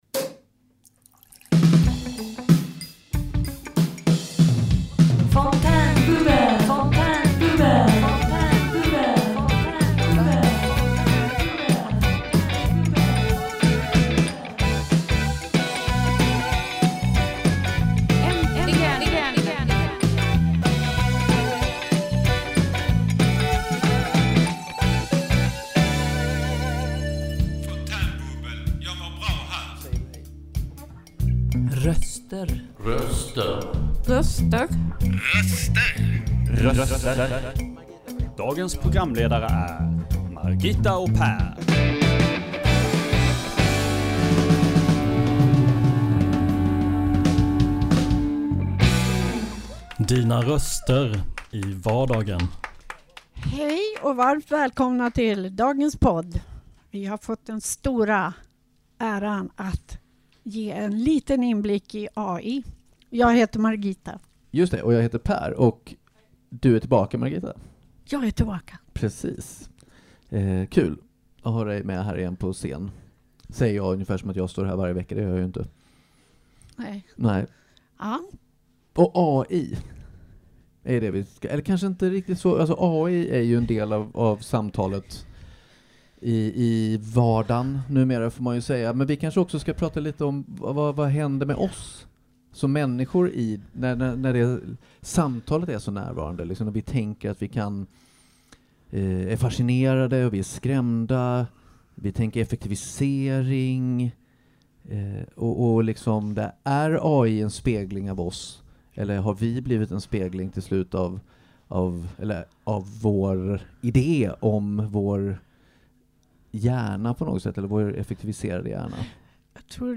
Personligt, humoristiskt och nära – Fontänbubbel är dina röster i vardagen!